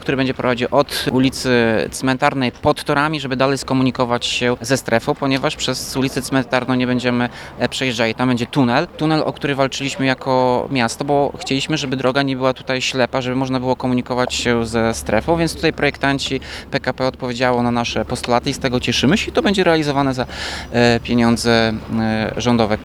Jak mówi Tomasz Andrukiewicz, tunel to dobre rozwiązanie.